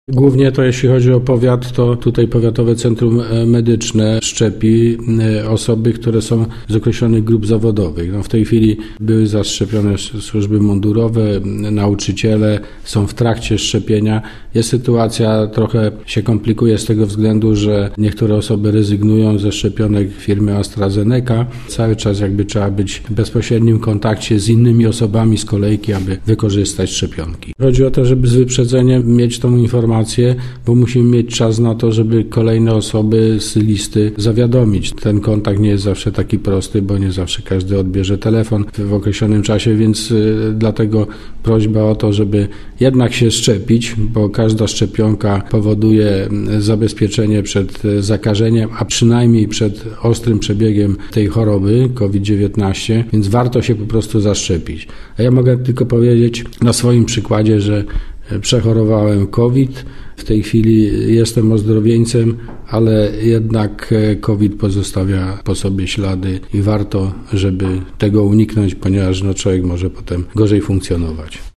– mówił starosta, Andrzej Szymanek.